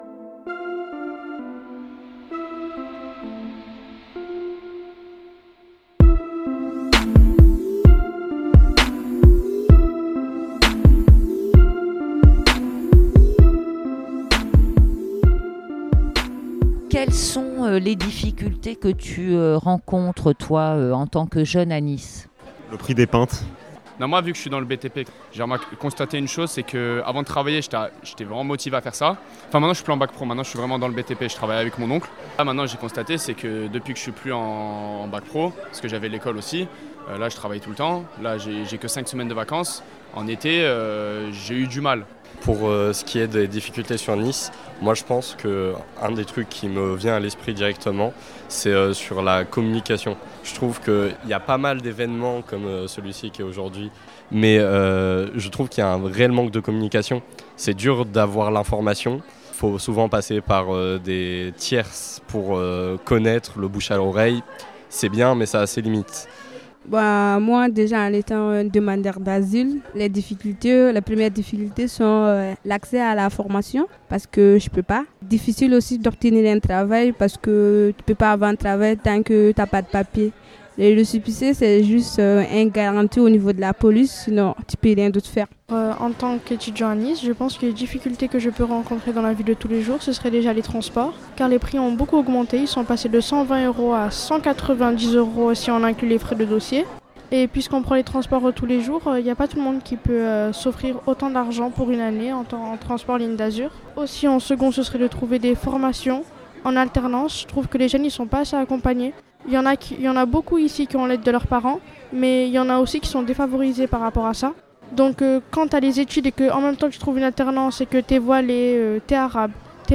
Vendredi 7 décembre à 18h30 sur la place de la Libération à Nice, s’est renouvelé le rassemblement des coquelicots, un appel national qui souhaite l’interdiction des pesticides de synthèse.